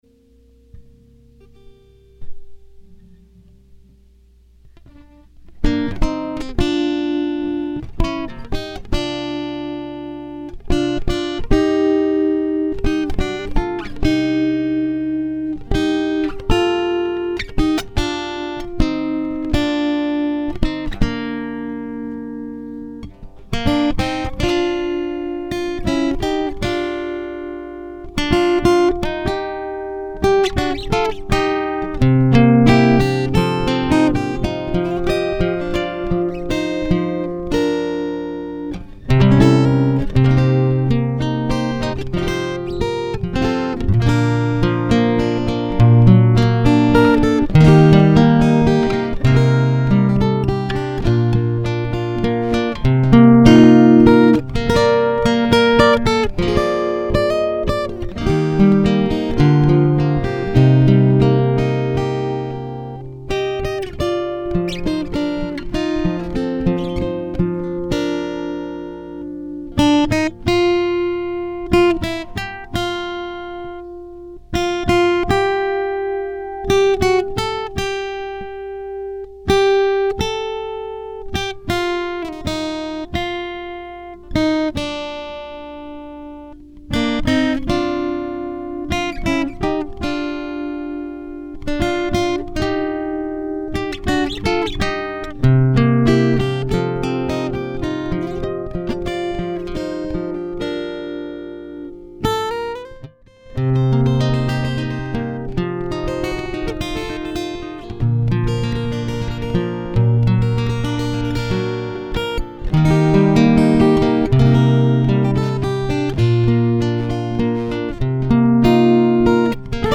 Vocals and music recorded live
Solo fingerstyle guitar.